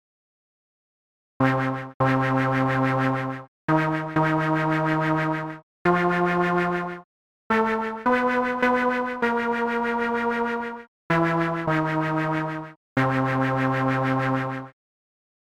d. Multiple Oscillators
1. Maak een extra [saw~] of [rect~] waarvan de frequentie nét iets meer dan een octaaf hoger is (door bijvoorbeeld de grondtoon te vermenigvuldigen met [* 2.01]).
2. Maak ook een extra [noise~] of [pink~] die je optelt bij de andere oscillators.